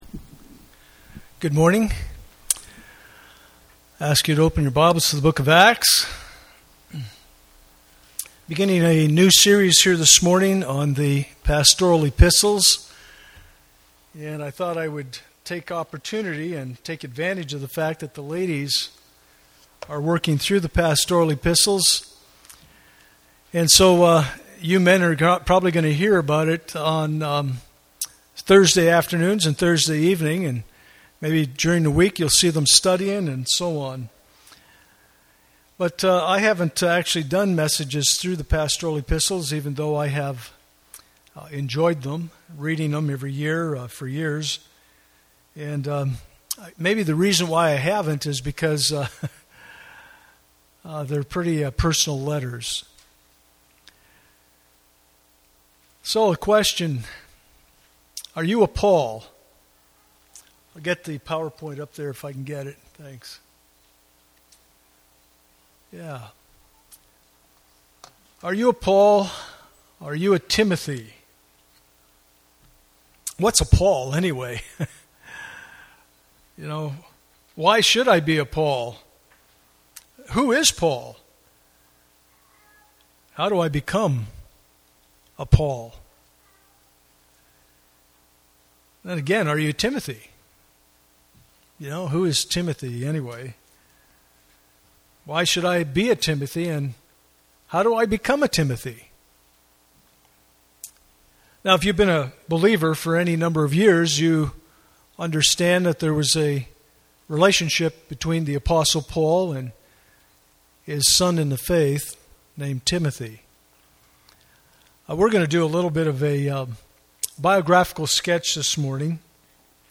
Passage: Acts 9:1-7 Service Type: Sunday Morning Posts pagination Previous 1 … 6 7